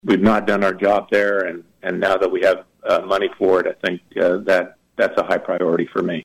Kansas 60th District Representative Mark Schreiber of Emporia joined KVOE’s Morning Show Thursday to offer his thoughts on the coming legislative session saying one of the main concerns expressed to him by constituents has been that of property tax relief.